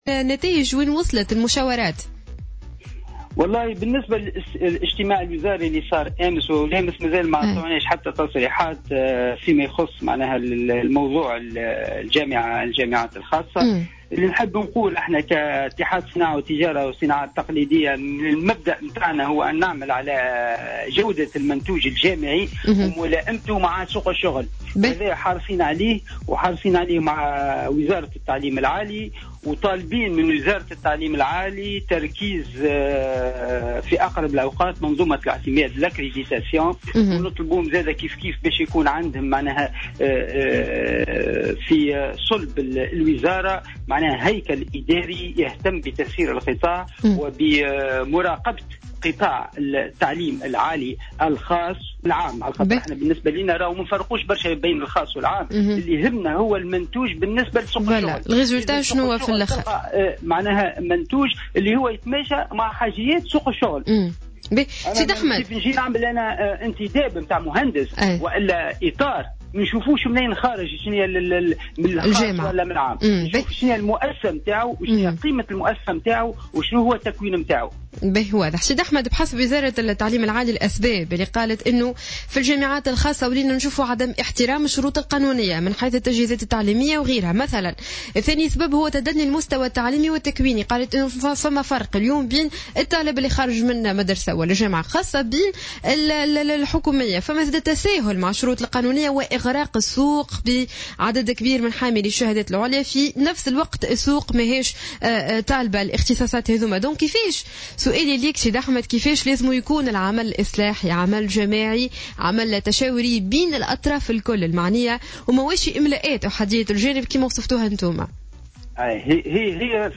في مداخلة على جوهرة "اف ام" صباح اليوم